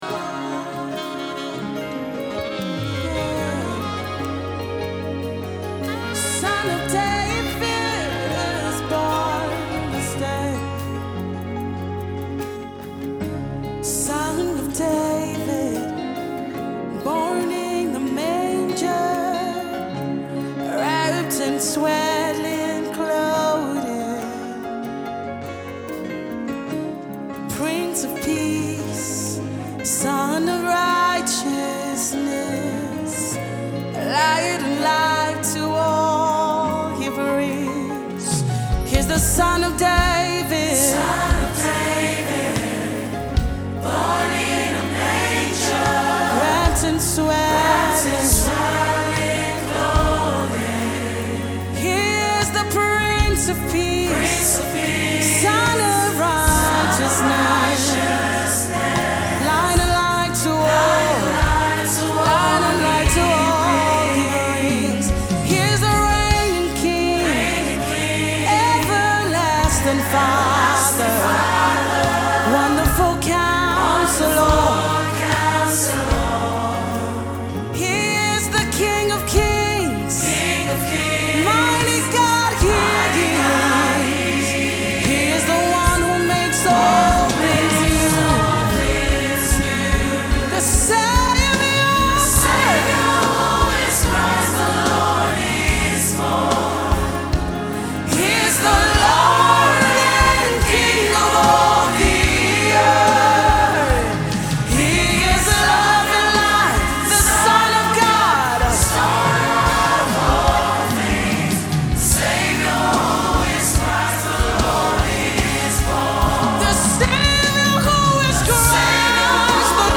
Christmas Songs